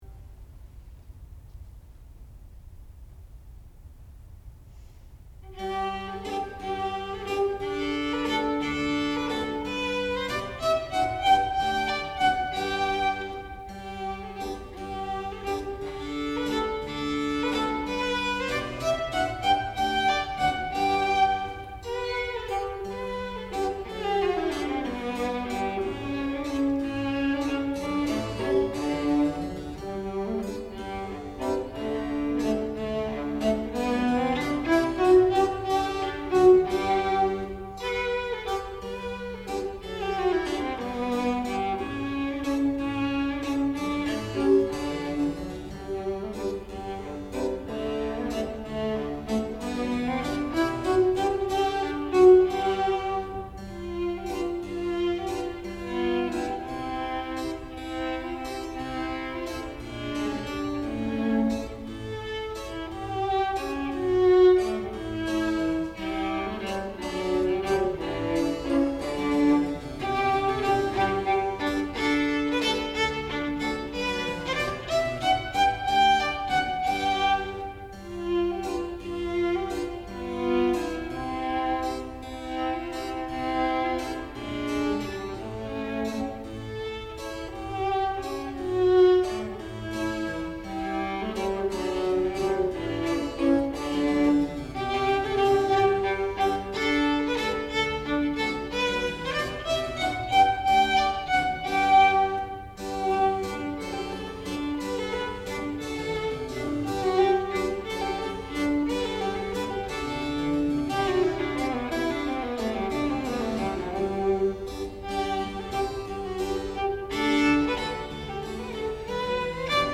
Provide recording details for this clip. Master's Recital